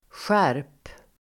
Uttal: [sjär:p]